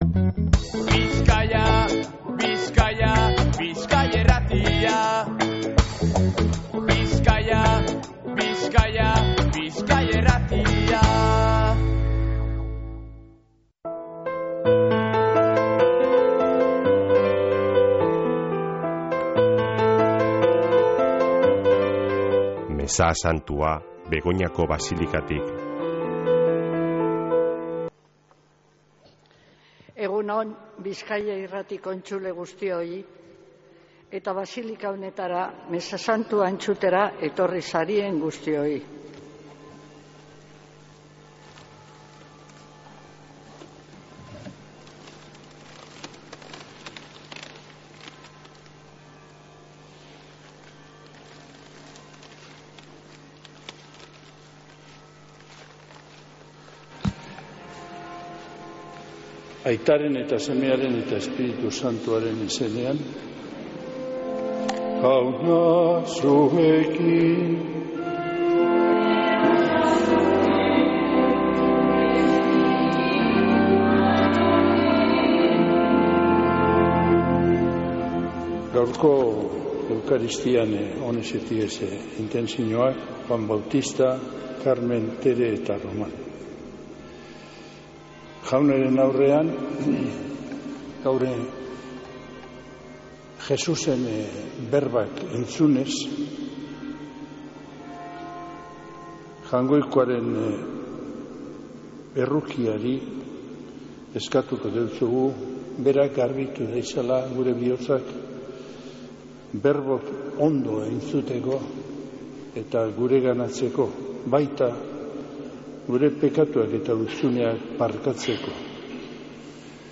Mezea Begoñako basilikatik | Bizkaia Irratia
Mezea (25-05-29)